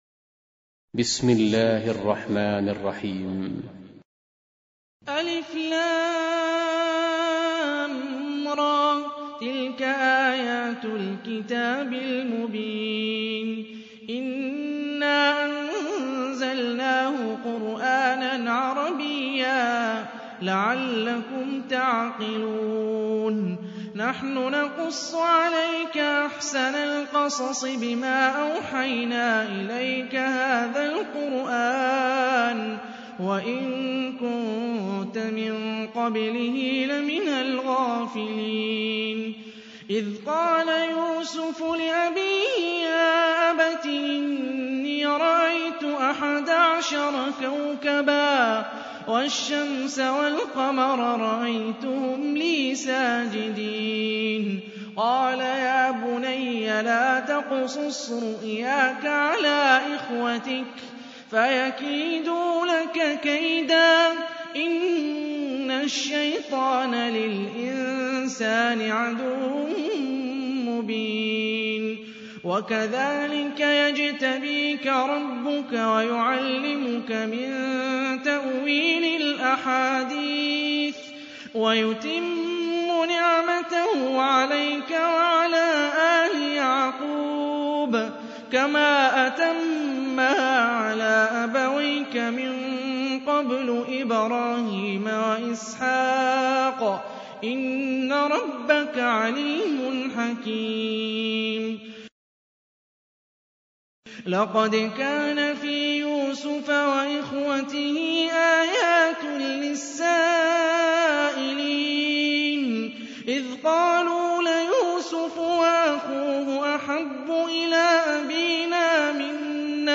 12. Surah Y�suf سورة يوسف Audio Quran Tarteel Recitation
Surah Repeating تكرار السورة Download Surah حمّل السورة Reciting Murattalah Audio for 12.